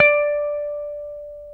Index of /90_sSampleCDs/Roland LCDP02 Guitar and Bass/GTR_Dan Electro/GTR_Dan-O 6 Str